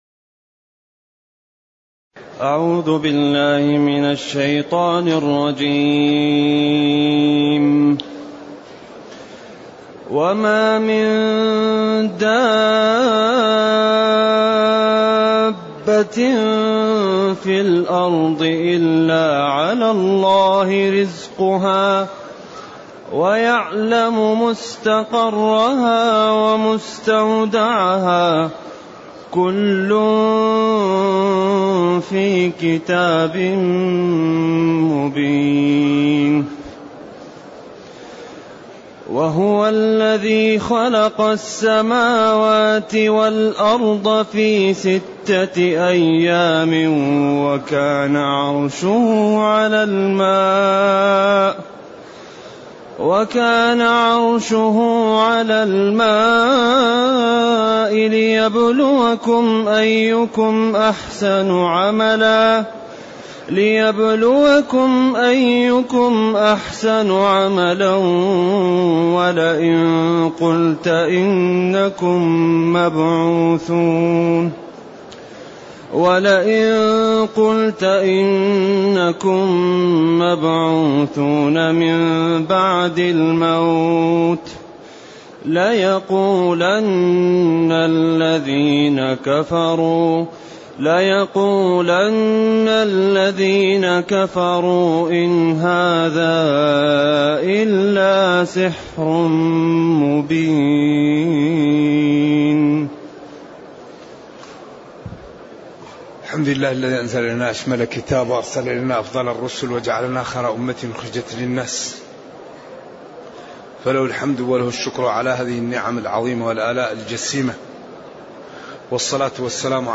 التصنيف: التفسير